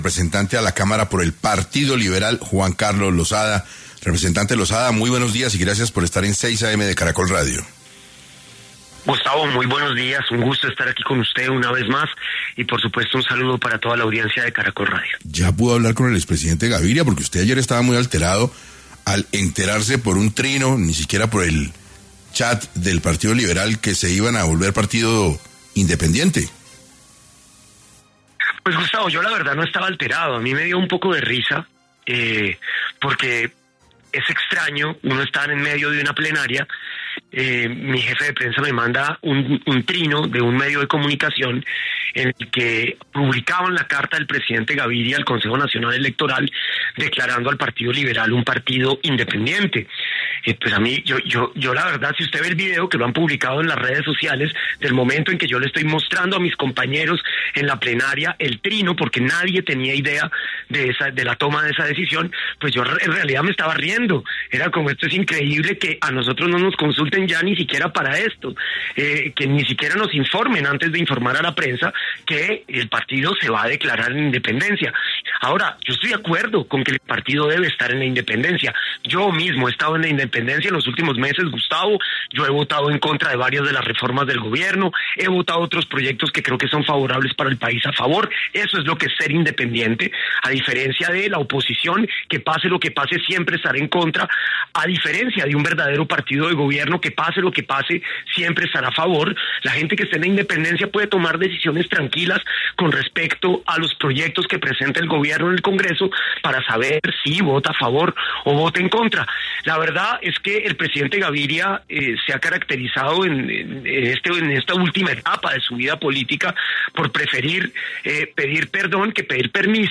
Juan Carlos Losada, representante, habló en 6AM sobre lo que piensa de la decisión de declararse el Partido Liberal independiente del gobierno Petro